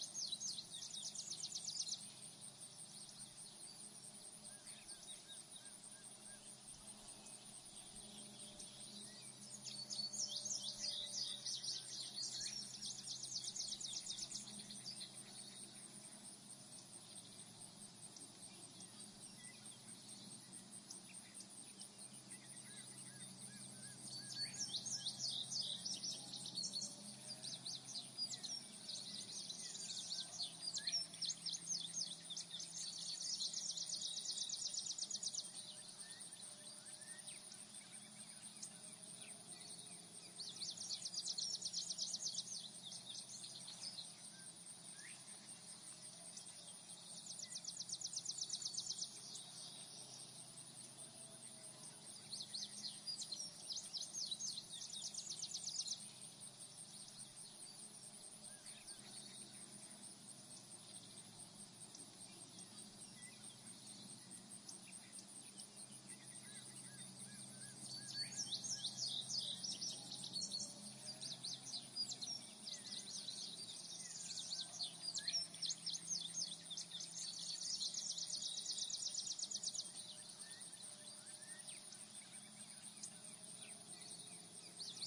Атмосферные звуки летнего поля для фона